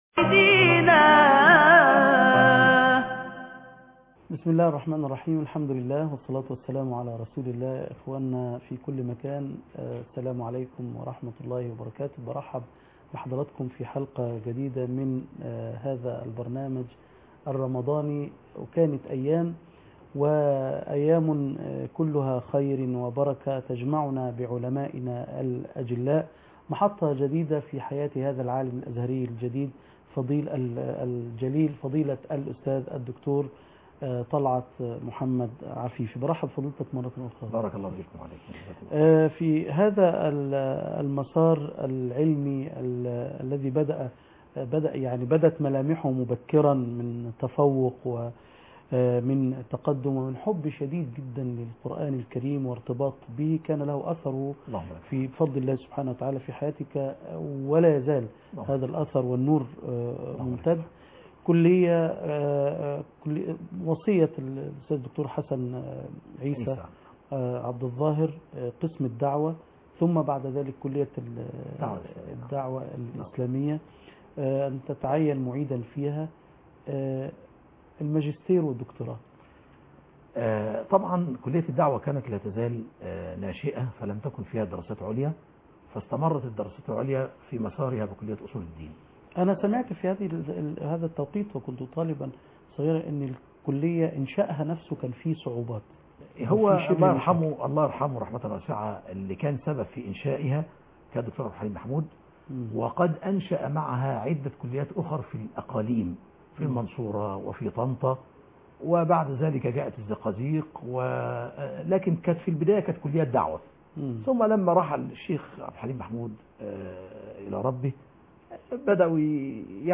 لقاء مع الدكتور طلعت عفيفى (15/8/2012) كانت أيام - قسم المنوعات